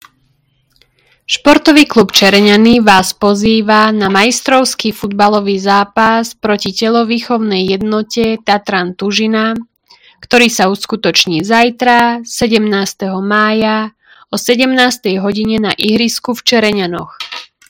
Hlásenie obecného rozhlasu – Futbal 17.05.2025 o 17:00